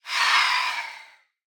1.21.5 / assets / minecraft / sounds / mob / phantom / idle4.ogg